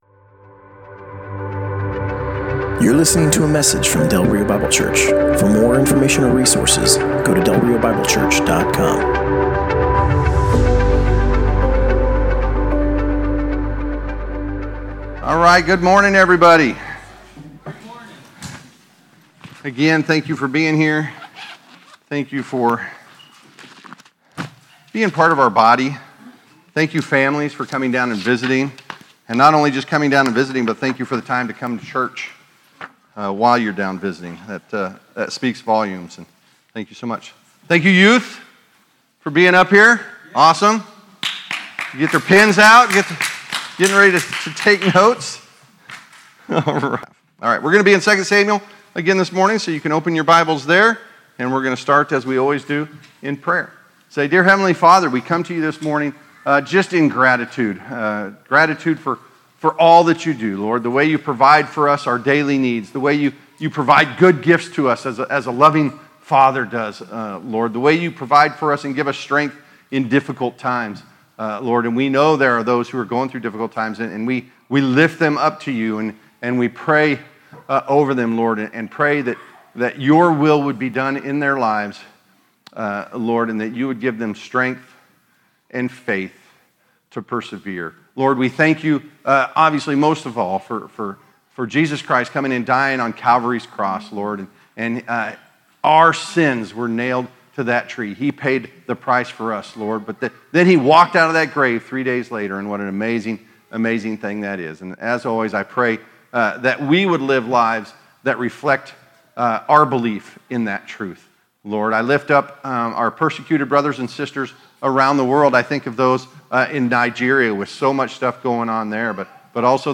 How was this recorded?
Passage: 2 Samuel 4:1 - 5:5 Service Type: Sunday Morning